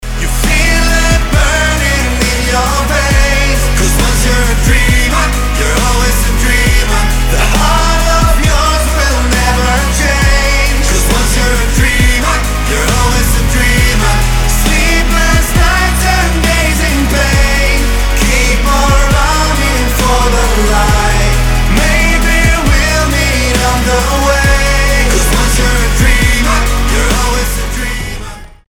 • Качество: 320, Stereo
позитивные
Pop Rock
Soft rock